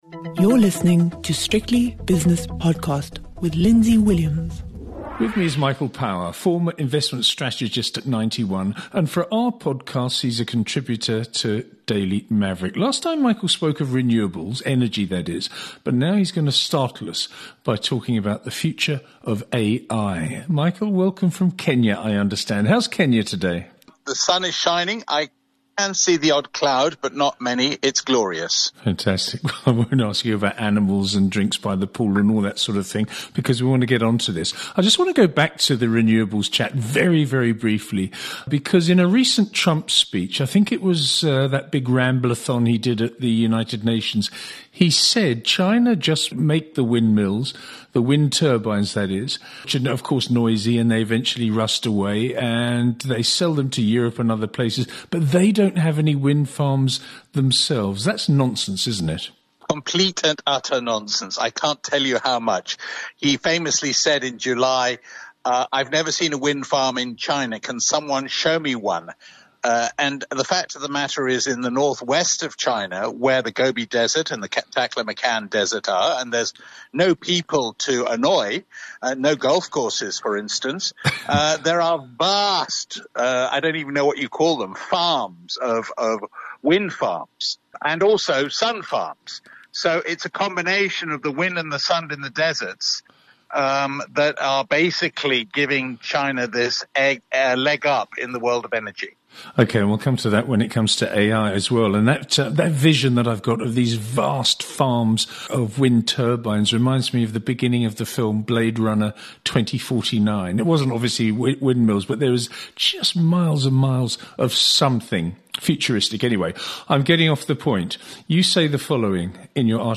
Hosting the best of business and market leaders' interviews and analysis.